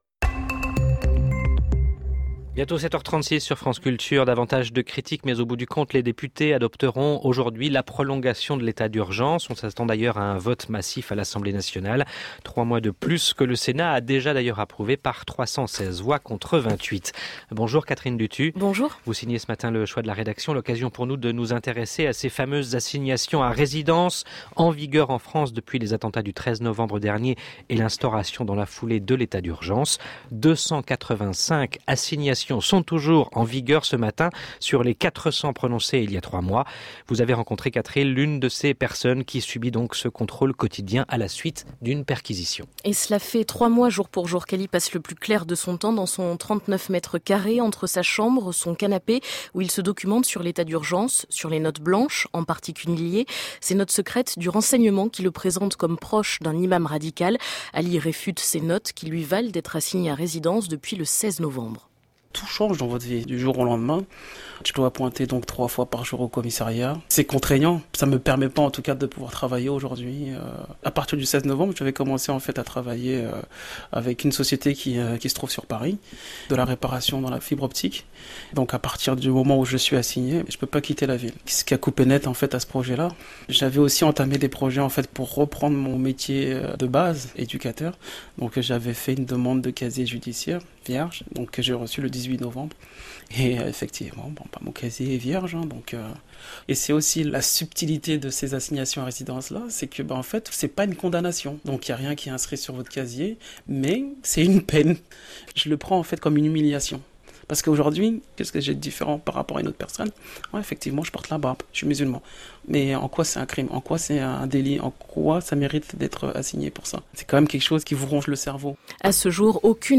France Culture le 16 février 2016 à 7h36